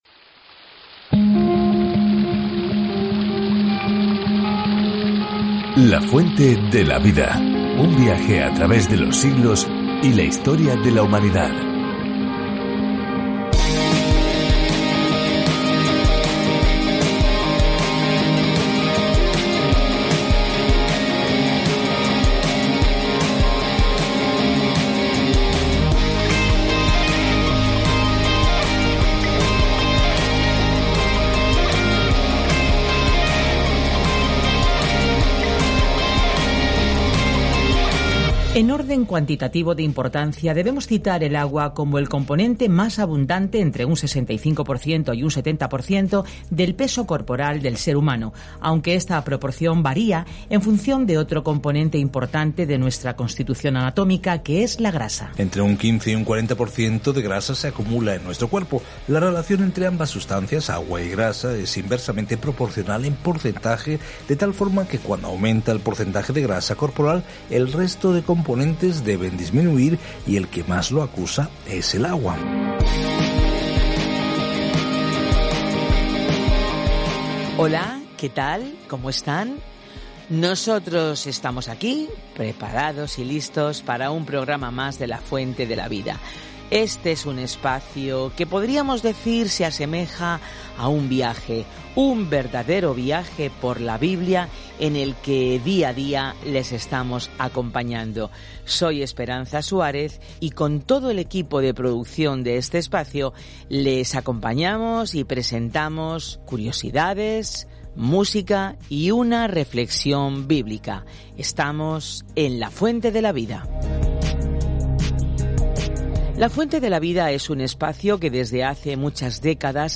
Viaja diariamente a través de Génesis mientras escuchas el estudio de audio y lees versículos seleccionados de la palabra de Dios.